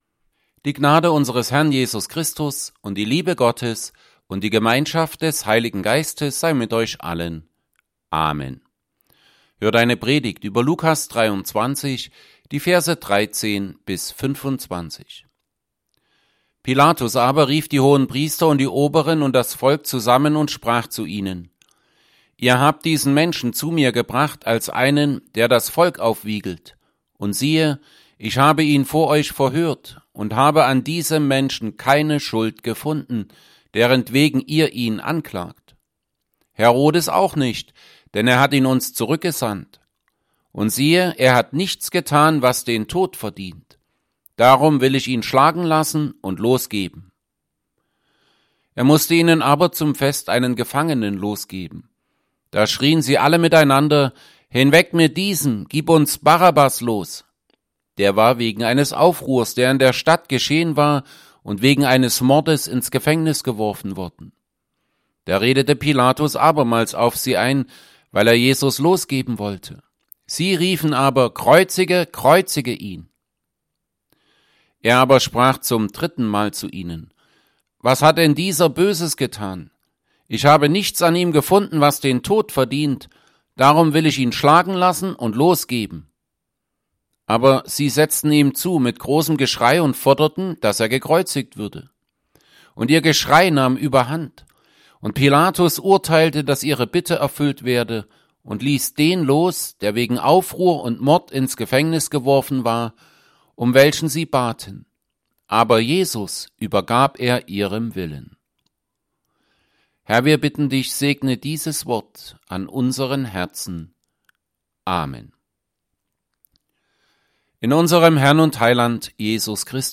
Evangelienpredigten Passage: Luke 23:13-25 Gottesdienst